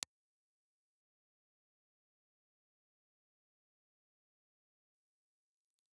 Ribbon
Cardioid
Impulse Response File:
Impulse Response file of National WM-706 microphone.
National_WM706_IR.wav